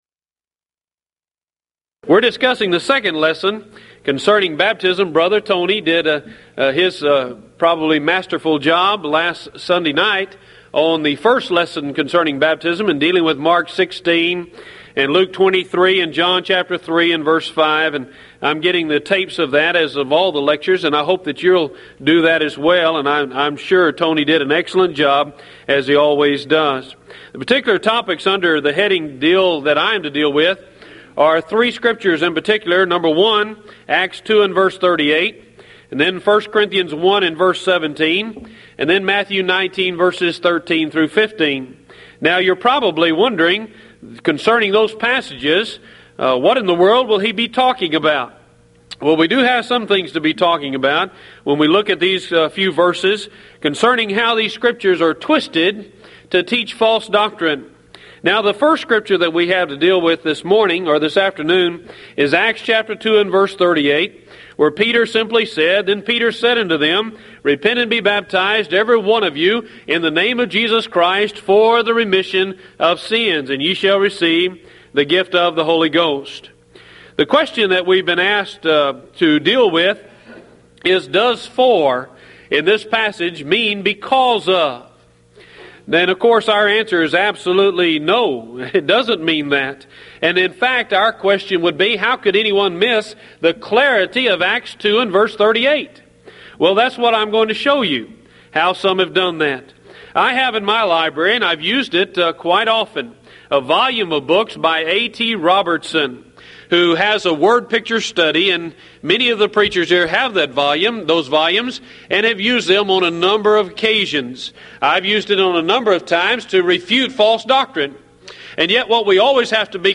Event: 1995 Mid-West Lectures
lecture